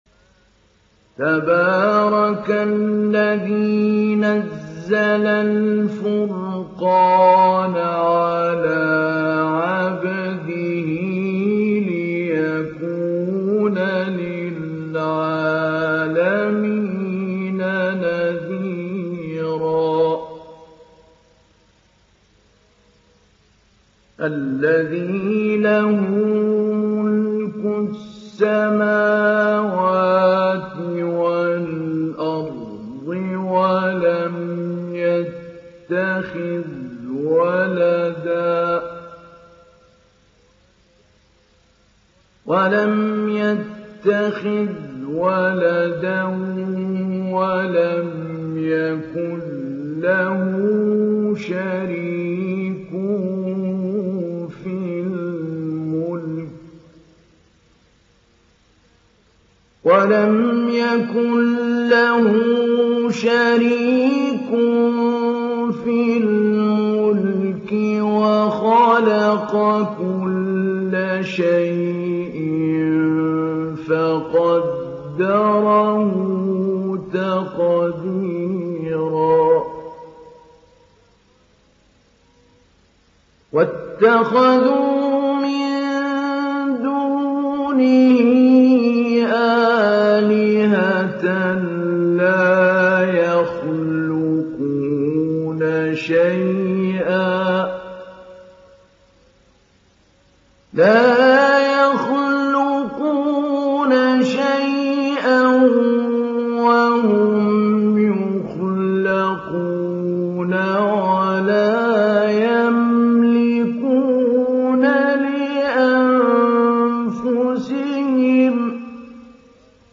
Surah Al Furqan Download mp3 Mahmoud Ali Albanna Mujawwad Riwayat Hafs from Asim, Download Quran and listen mp3 full direct links
Download Surah Al Furqan Mahmoud Ali Albanna Mujawwad